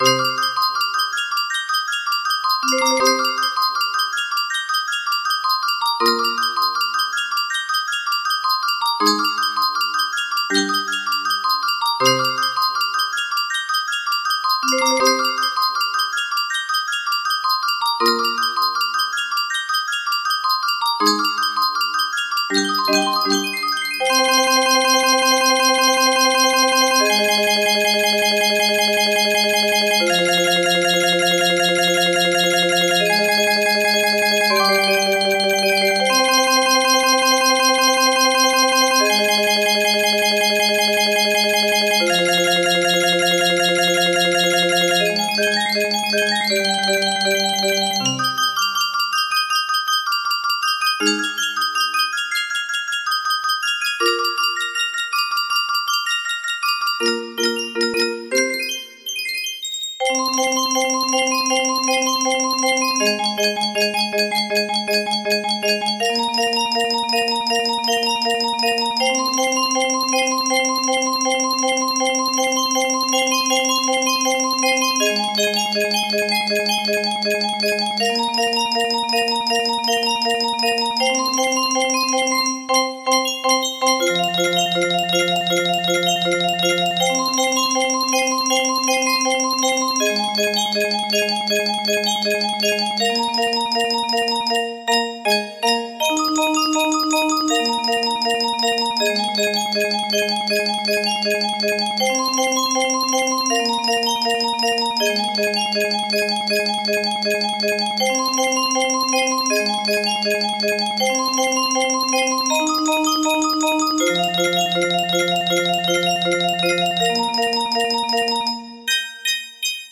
Full range 60
Arranged For Music Box